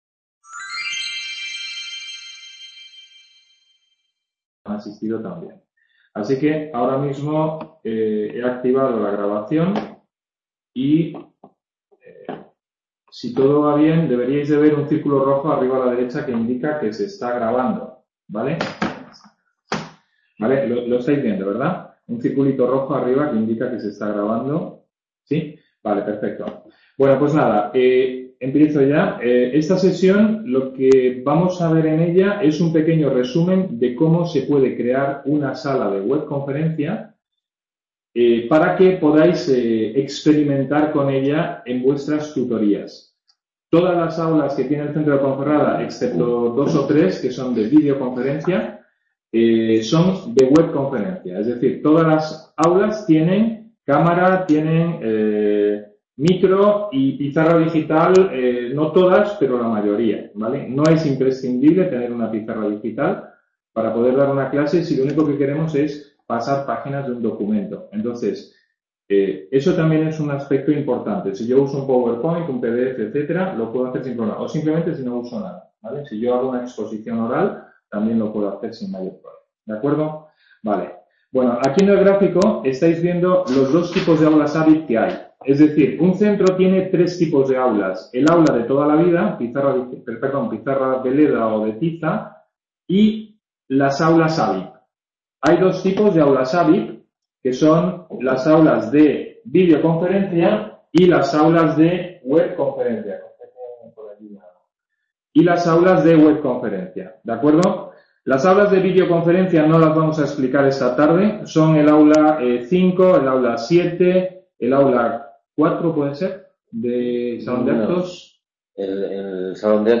Sesión de repaso sobre la plataforma AVIP dirigida a tutores del CA Ponferrada.__Se ha hecho hincapié en Webconferencia y en el Etiquetado y Publicación de Grabaciones